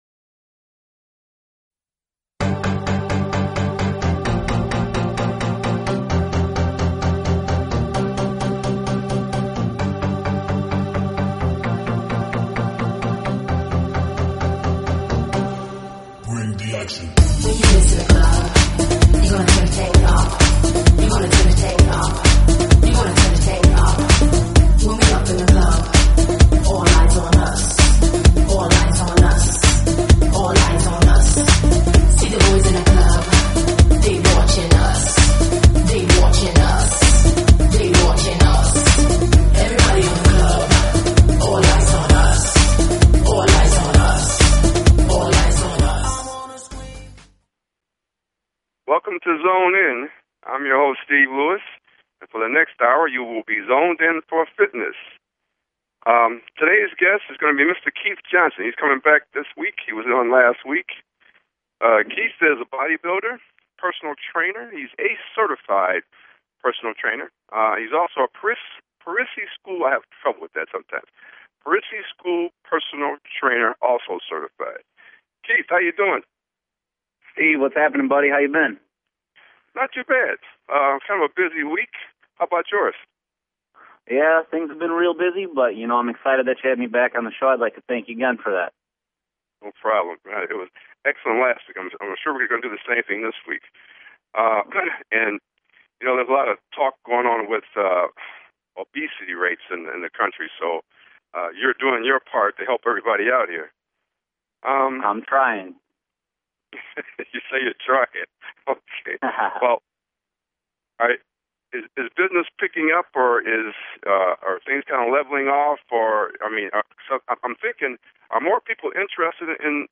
Talk Show Episode, Audio Podcast, Zone In!